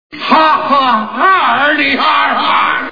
The Honeymooners TV Show Sound Bites